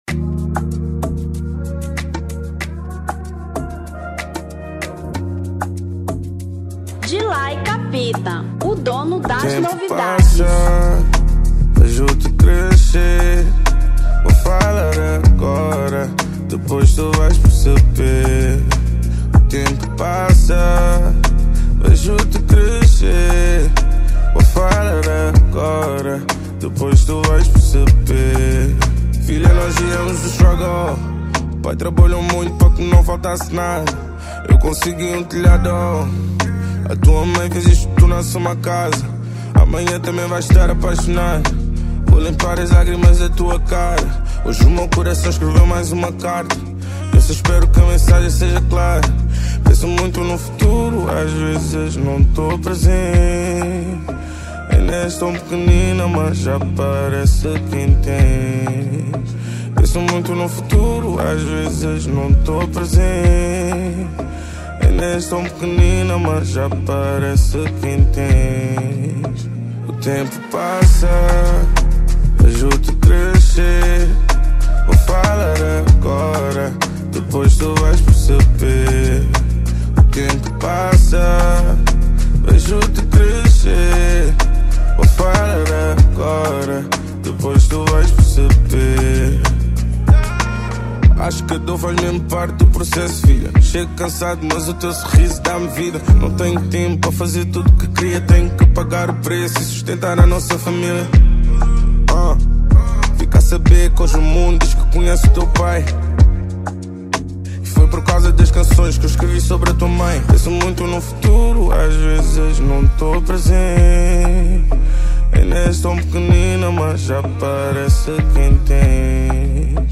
Zouk 2025